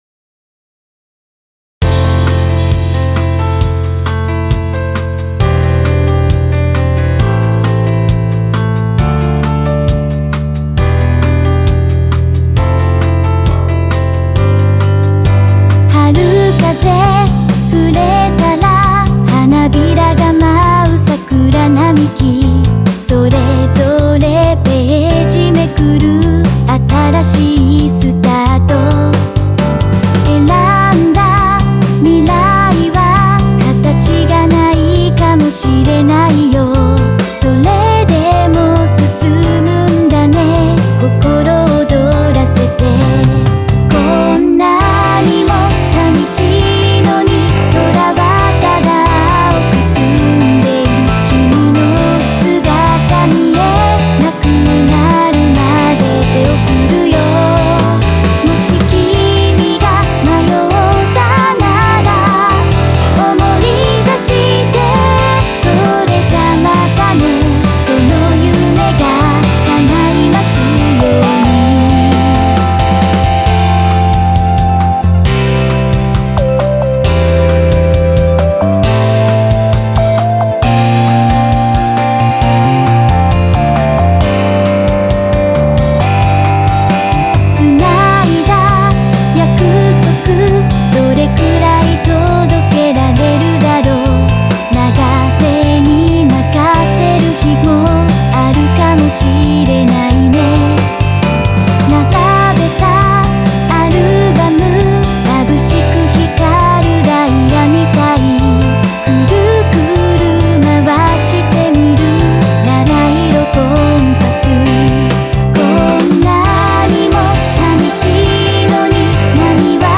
「歌モノ部門」最優秀作品です。
・Synthesizer V GUMI
・Eギター、Aギター、ストリングス、ピアノ、シンセ